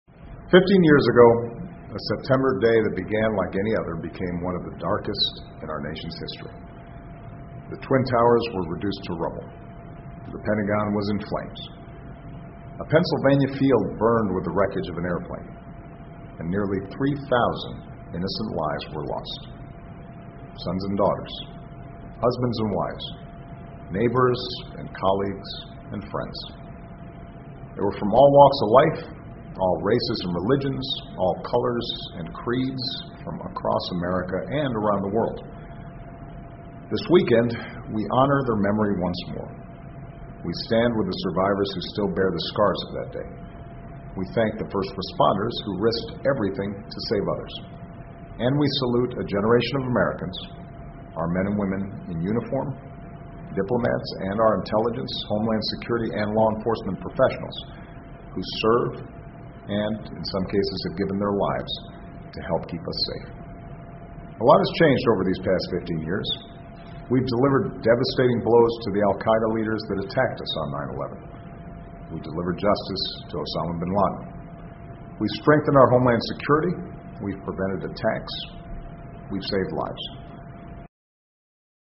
奥巴马每周电视讲话：总统呼吁继承9.11曾经失去的财富（01） 听力文件下载—在线英语听力室